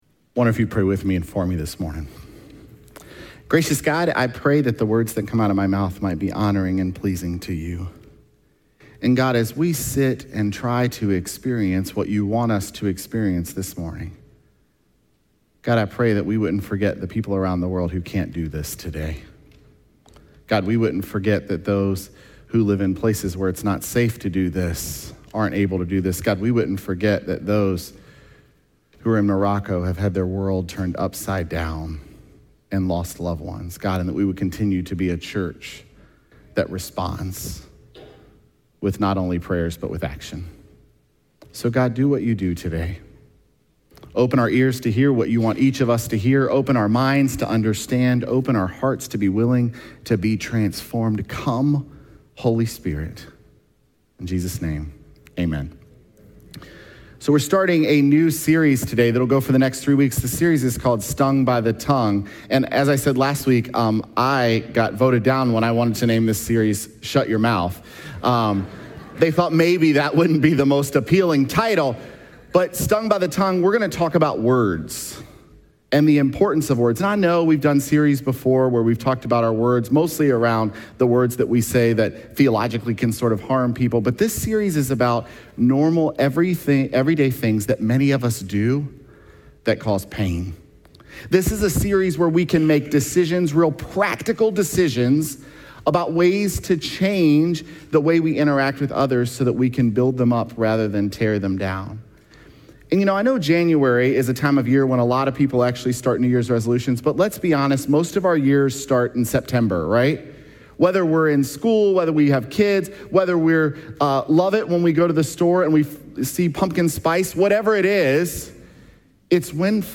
Sept10Sermon.mp3